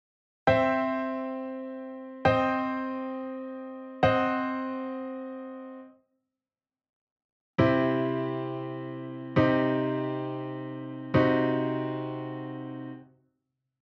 ↓の音源は、C・Cm・Cm-5を順番に鳴らした例です。